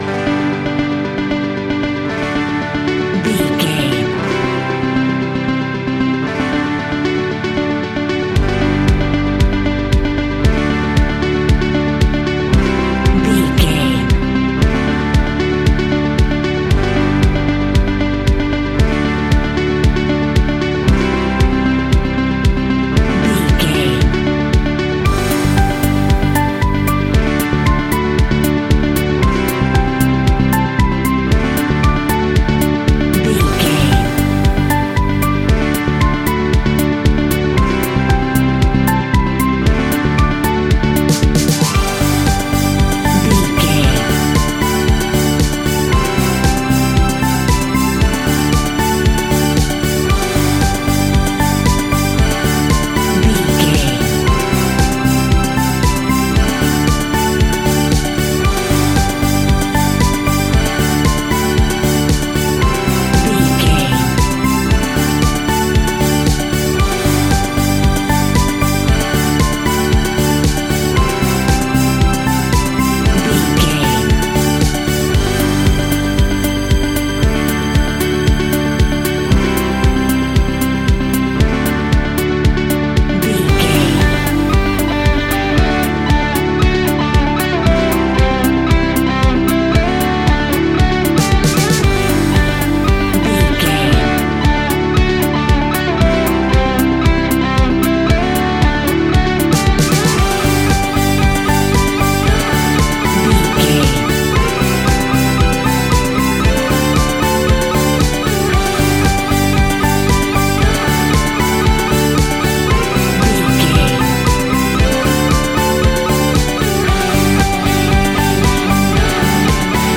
Disco Pop Indie Rock.
Ionian/Major
energetic
uplifting
instrumentals
upbeat
groovy
guitars
bass
drums
piano
organ